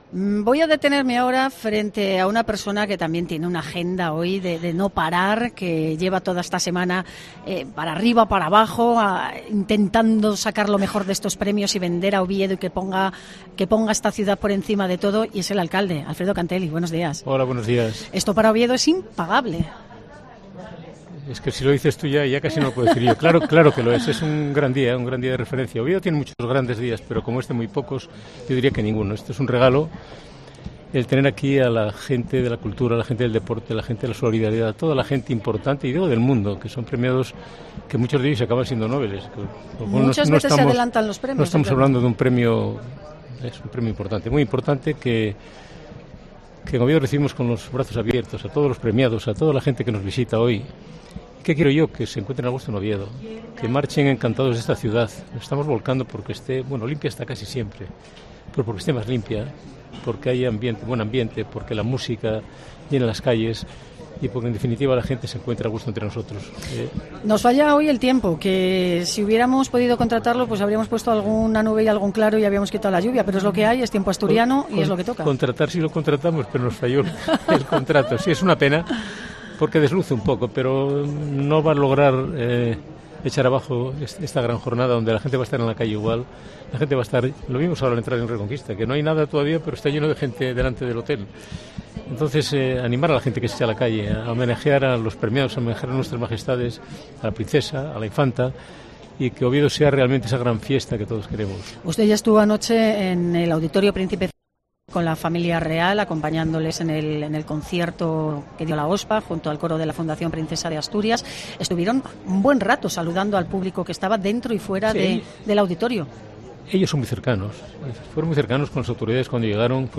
Entrevista a Alfredo Canteli, alcalde de Oviedo, en el Especial Premios Princesa de Asturias de COPE